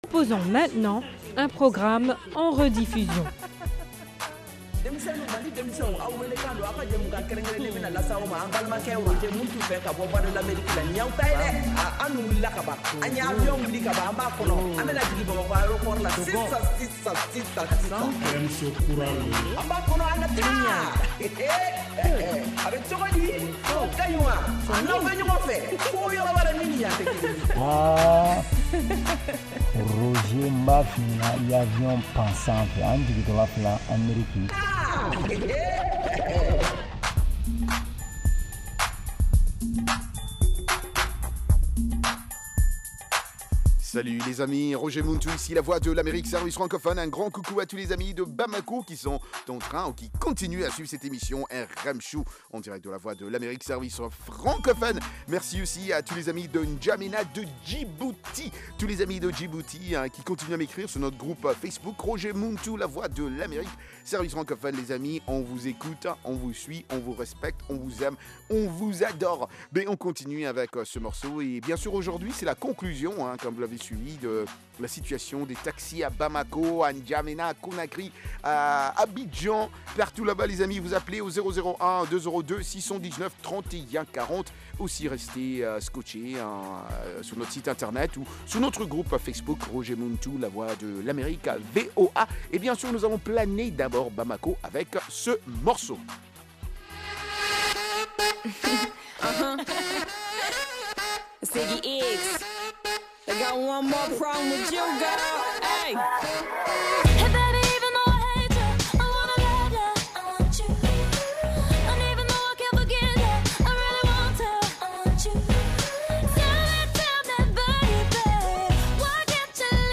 Emission quotidienne de musique et d’entretien avec les auditeurs.
une sélection spéciale de musique malienne et internationale.